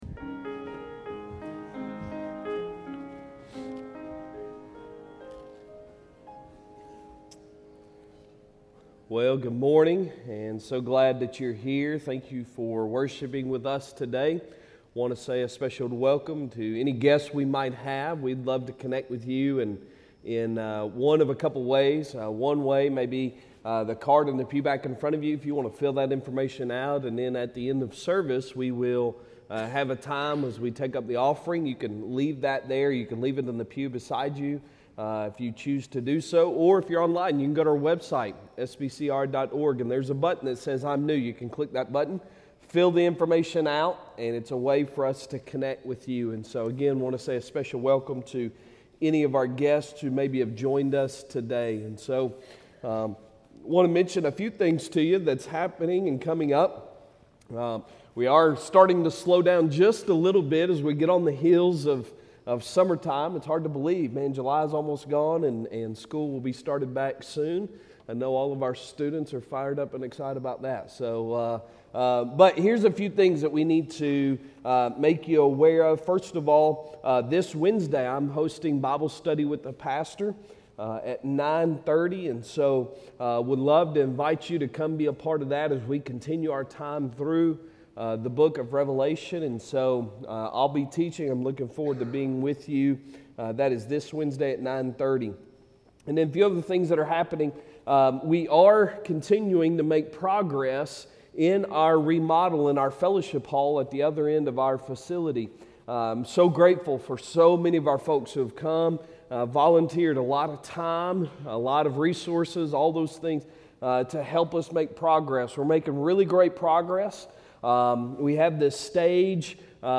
Sunday Sermon July 30, 3023